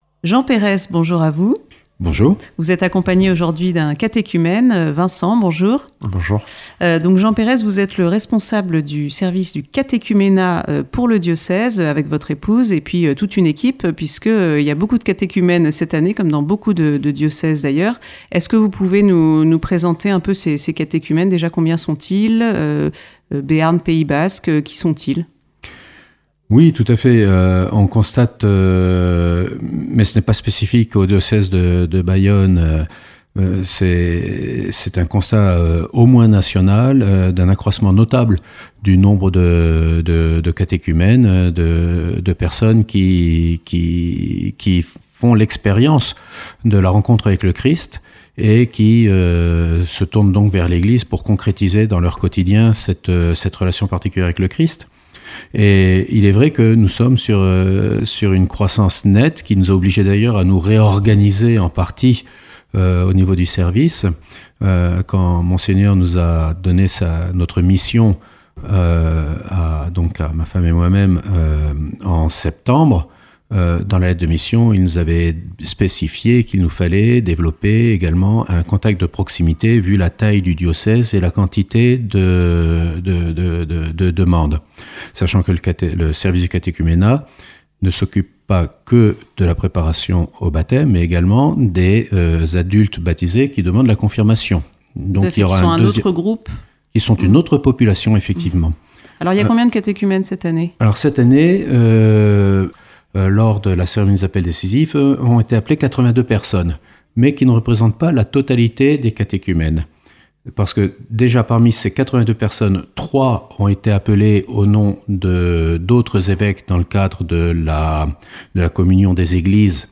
entretiendujeudi